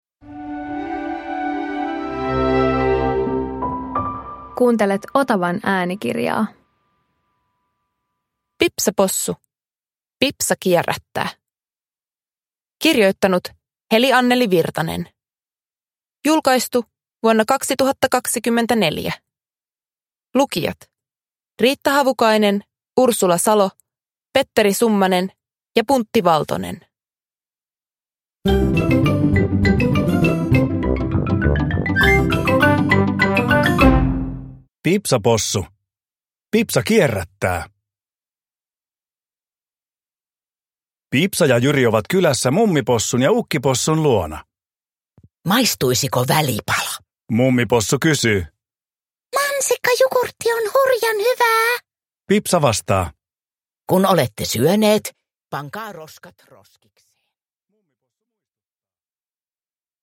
Pipsa Possu - Pipsa kierrättää – Ljudbok